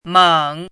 chinese-voice - 汉字语音库
meng3.mp3